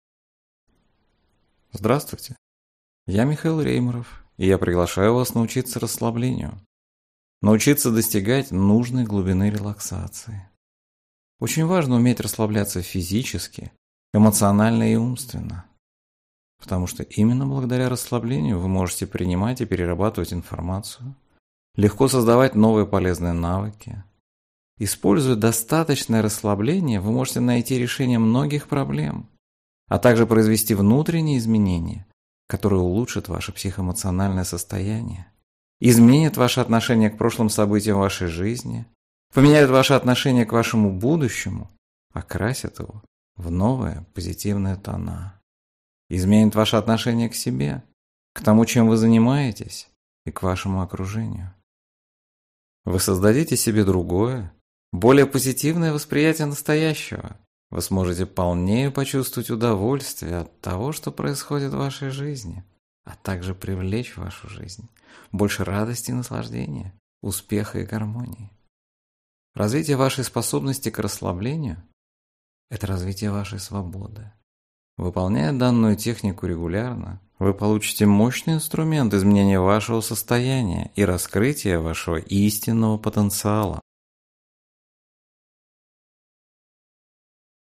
Аудиокнига Отдохни. Лучшие техники для релаксации | Библиотека аудиокниг